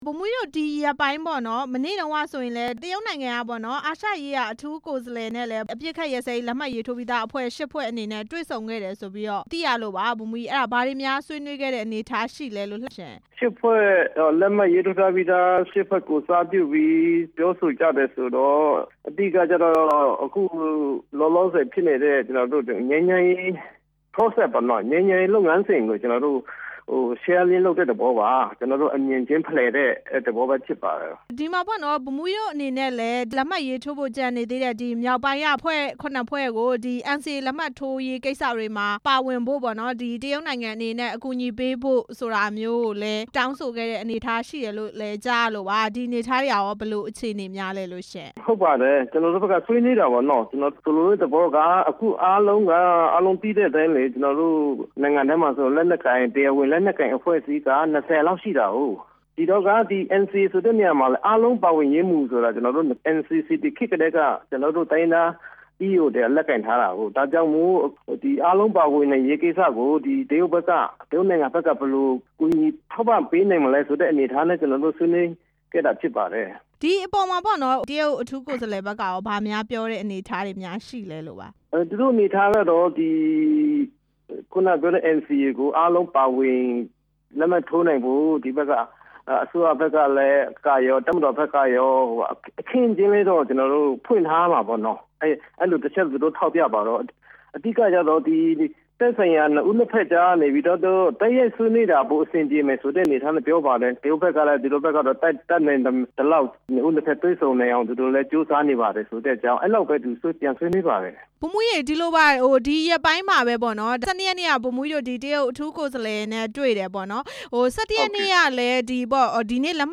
အပစ်ရပ် ၈ ဖွဲ့နဲ့ တရုတ် အထူးကိုယ်စားလှယ် တေ့ွဆုံခဲ့တဲ့အကြောင်း မေးမြန်းချက်